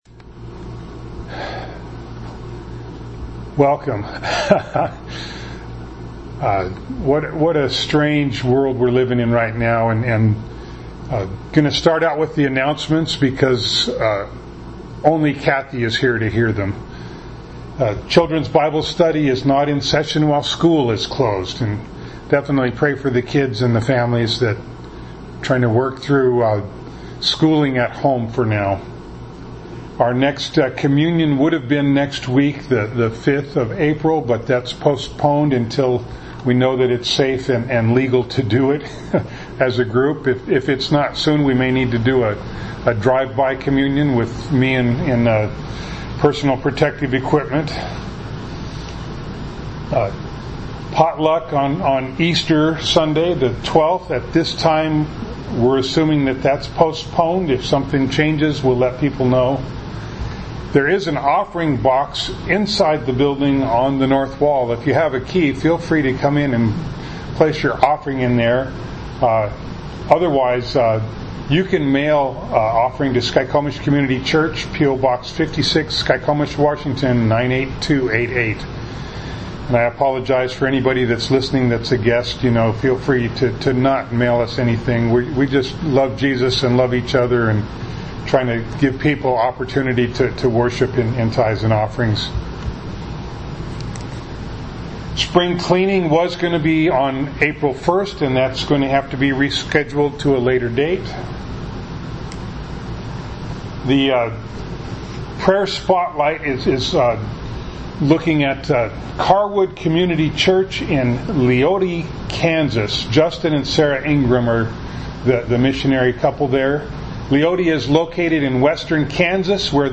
Ephesians 5:3 Service Type: Sunday Morning Bible Text